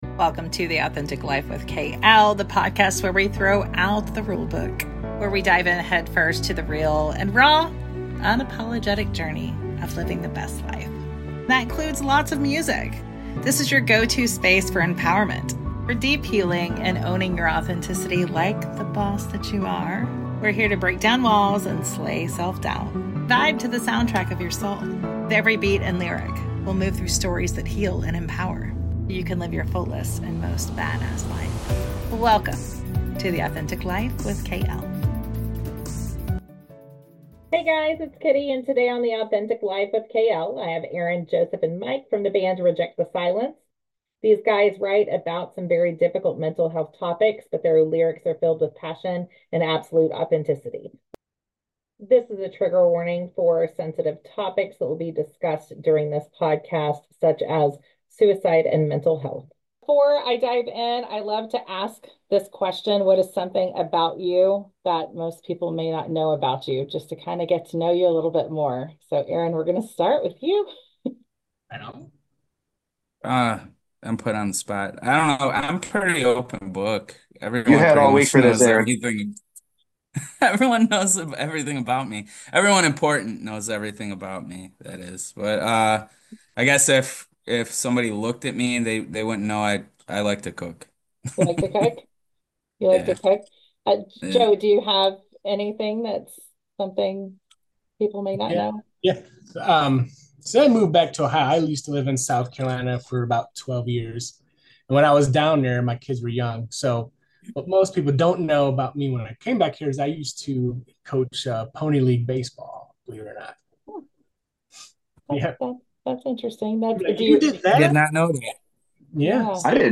Authenticity is the pillar of the show and that's exactly what we got with real and raw conversation. Trigger Warning: this episode contains sensitive topics being openly discussed: suicide and mental health. REJECT THE SILENCE IS A BAND THAT ARE PROUD ADVOCATES FOR MENTAL HEALTH, SUICIDE PREVENTION AND HELPING OTHERS.